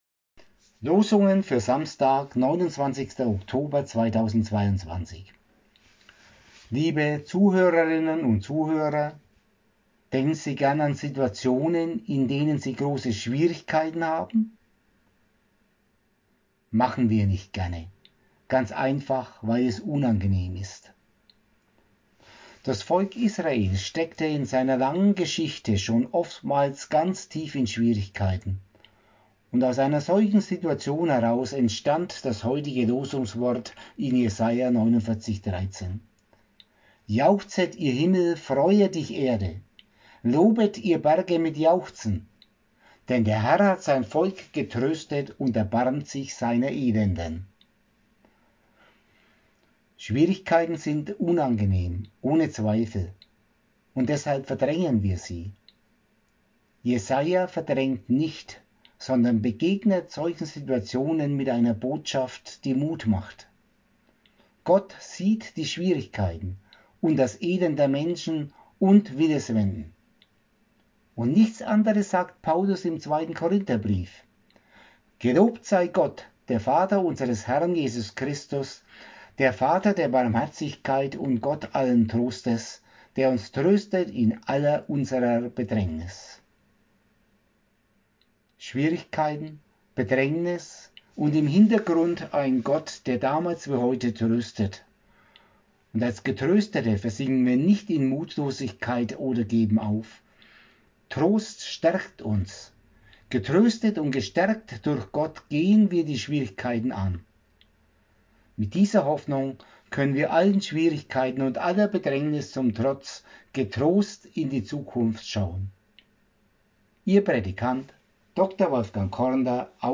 Impuls zur Tageslosung